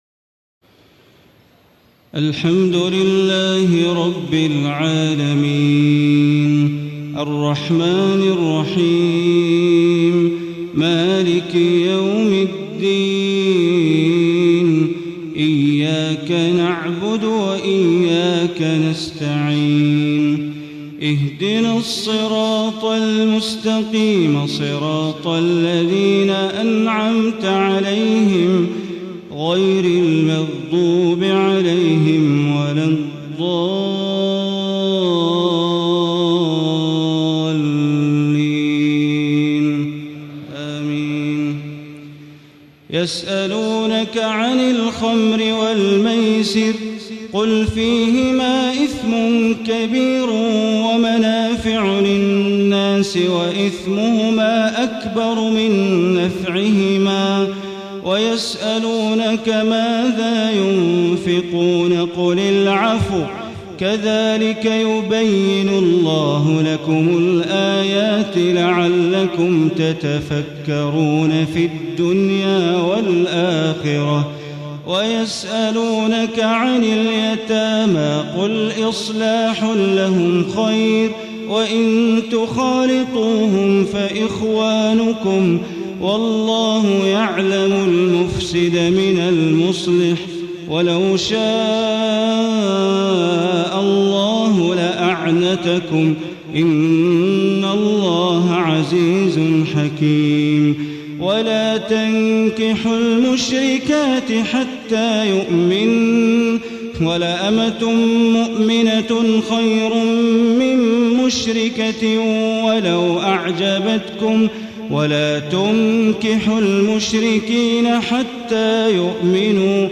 تهجد ليلة 22 رمضان 1435هـ من سورة البقرة (219-252) Tahajjud 22 st night Ramadan 1435H from Surah Al-Baqara > تراويح الحرم المكي عام 1435 🕋 > التراويح - تلاوات الحرمين